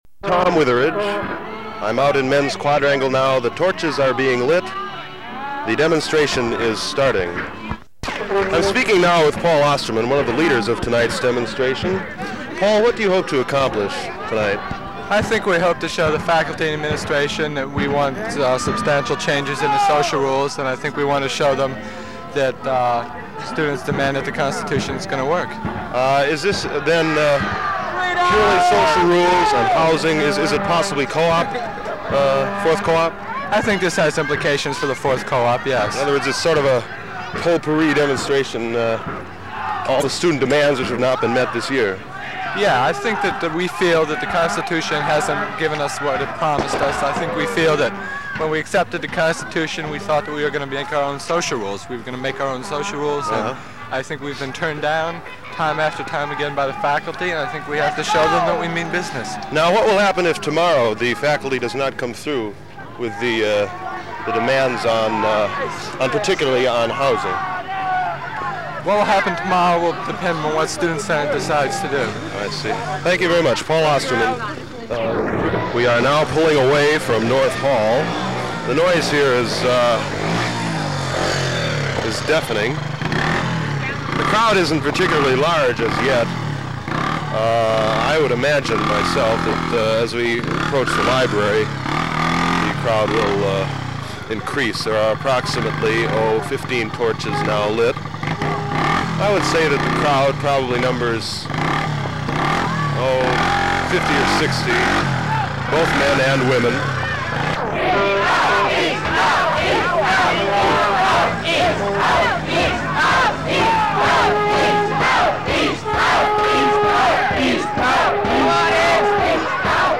As people shouted and a bass drum thumped in the distance,